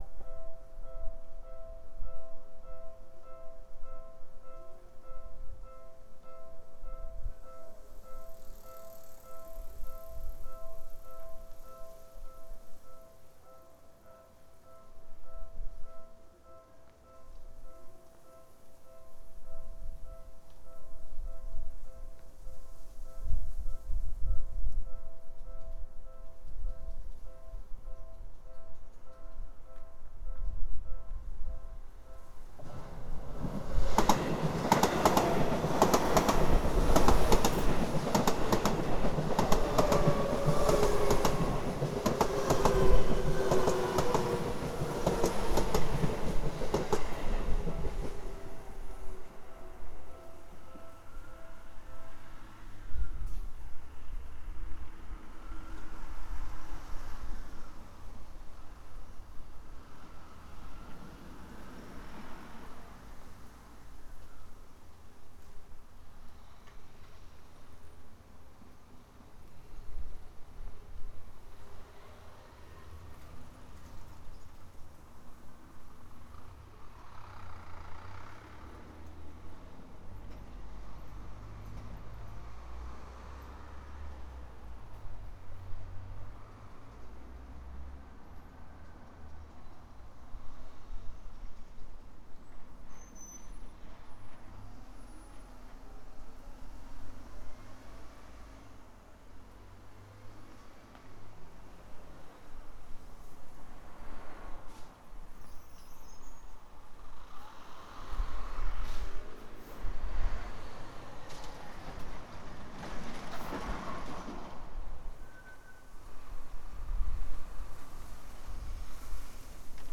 レコーダーはDR-100、内蔵マイク
風が少し強かったのですが、
途中でレールの継ぎ目にマイクを向けました。
そのあとにラビューが通過。
内蔵マイク 指向性ステレオマイク